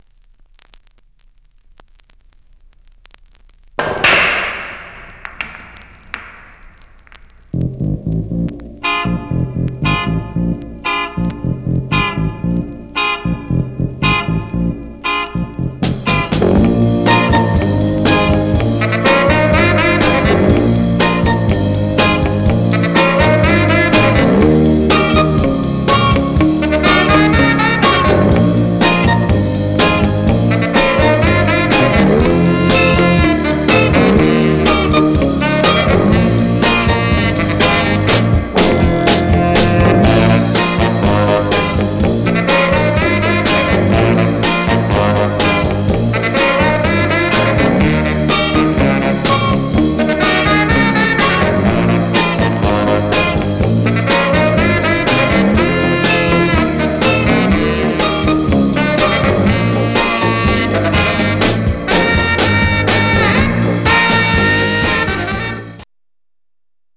Condition: VG+ dj or M- copy stock popcorn $50.00
Some surface noise/wear
Mono
R & R Instrumental